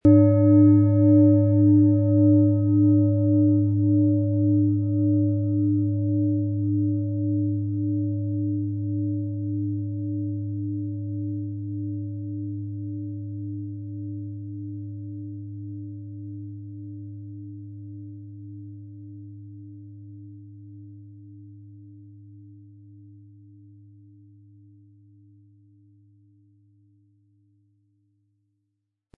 Planetenschale® Neue Ideen bekommen & Altes aufgeben mit Uranus, Ø 24 cm, 1500-1600 Gramm inkl. Klöppel
Planetenton 1
Von erfahrenen Fachkräften in einem indischen Dorf wurde diese Uranus Klangschale von Hand hergestellt.
Im Sound-Player - Jetzt reinhören können Sie den Original-Ton genau dieser Schale anhören.
PlanetentonUranus
MaterialBronze